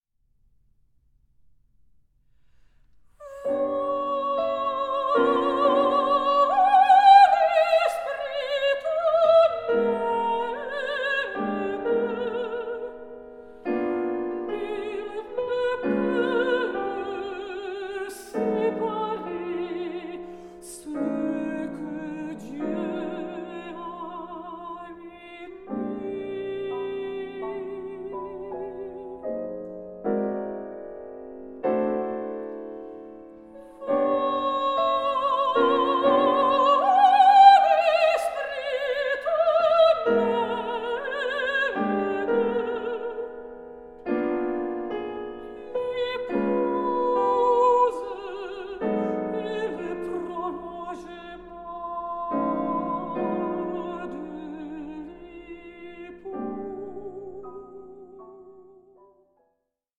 mezzo-soprano
pianist